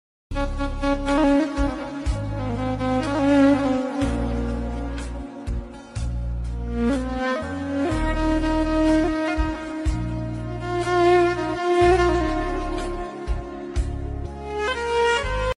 New Emotional Sad 8k Ringtone For mobile